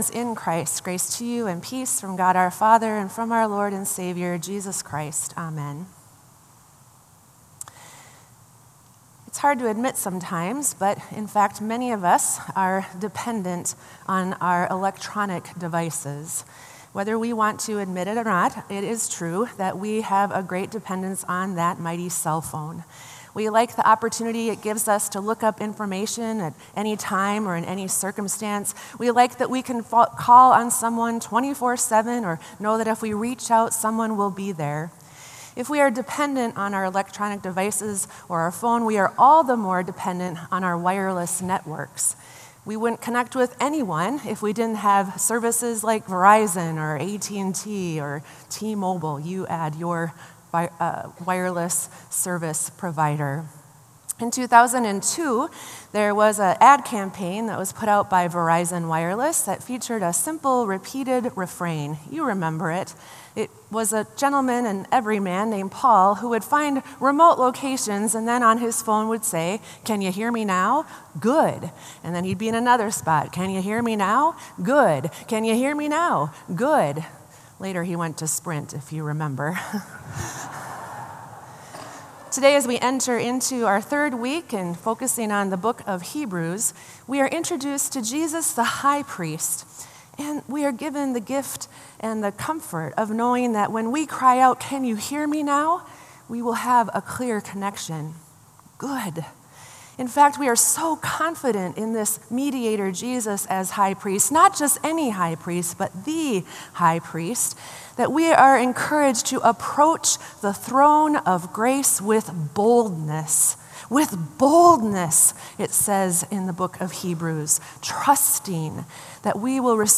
Sermon “I Know How You Feel” | Bethel Lutheran Church